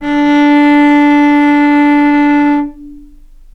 vc-D4-mf.AIF